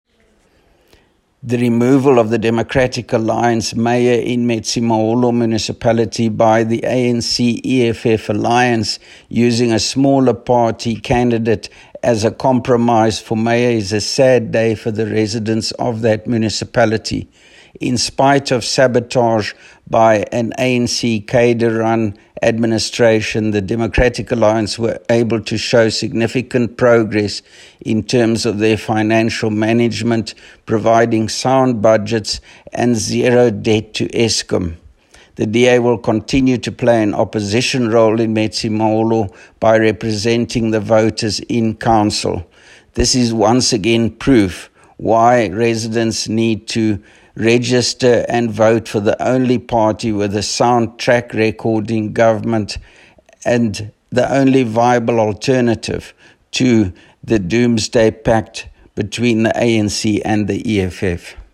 Afrikaans soundbites by Roy Jankielsohn MPL